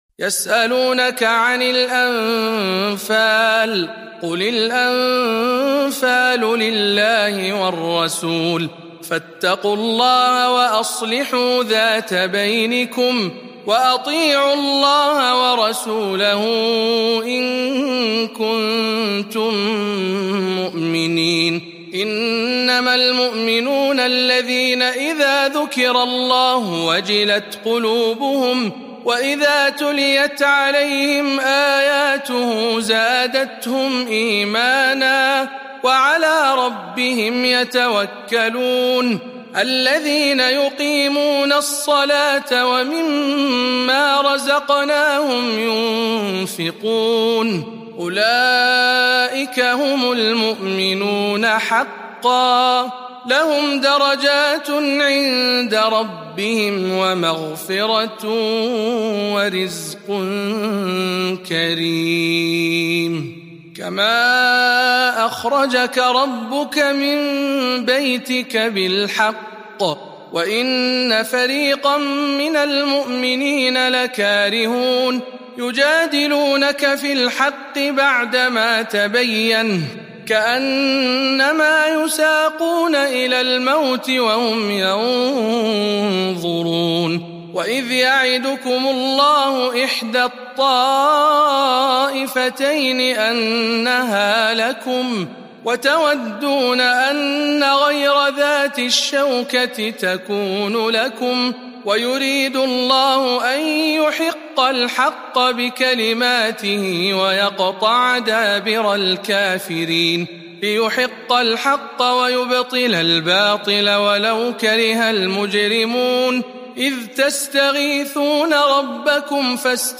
008. سورة الأنفال برواية شعبة عن عاصم - رمضان 1441 هـ